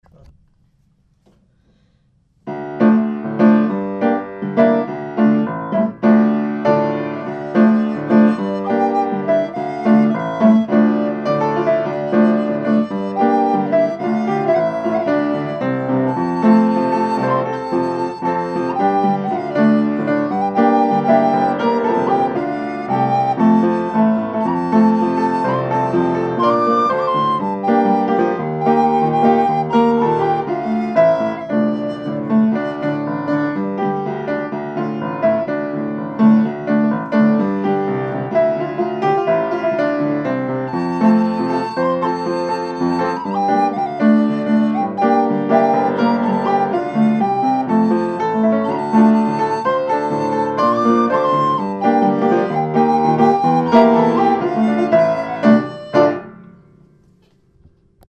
אני חלילית